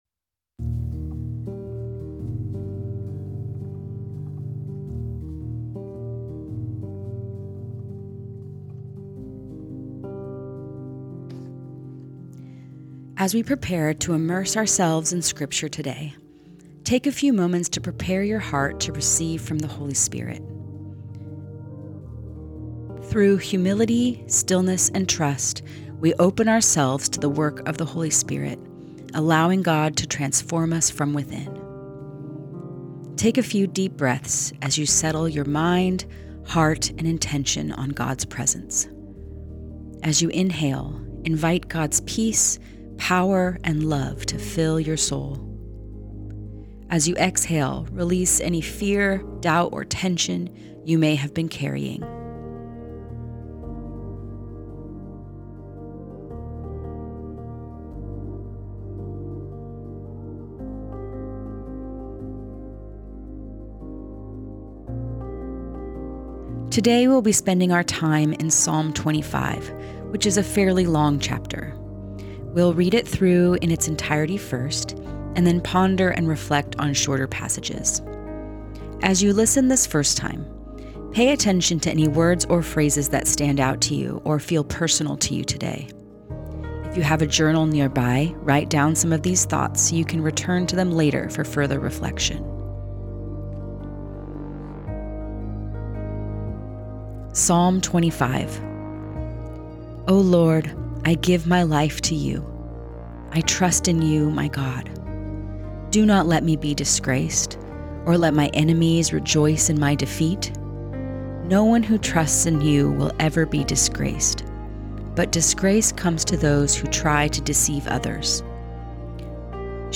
Guided Listening Practice Prepare As we prepare to immerse ourselves in Scripture today, take a few moments to prepare your heart to receive from the Holy Spirit.